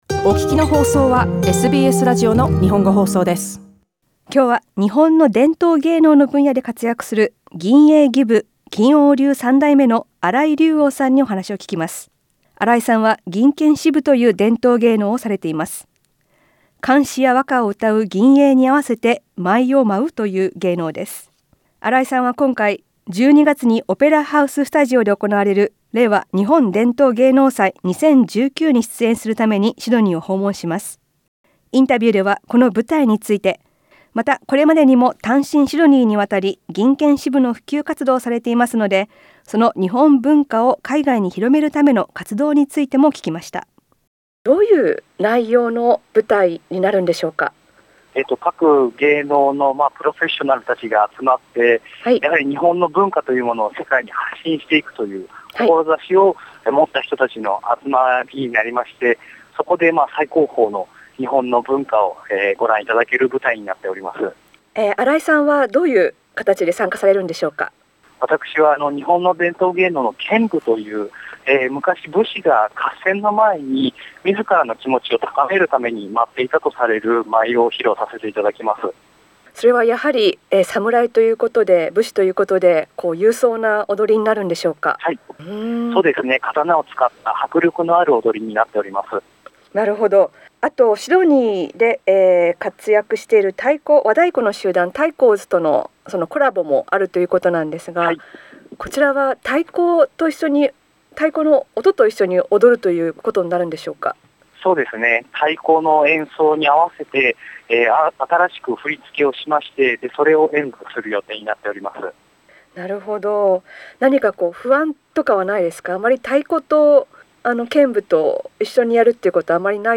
インタビューでは、剣舞やオーストラリア滞在時の思い出、今回の舞台などについて聞きました。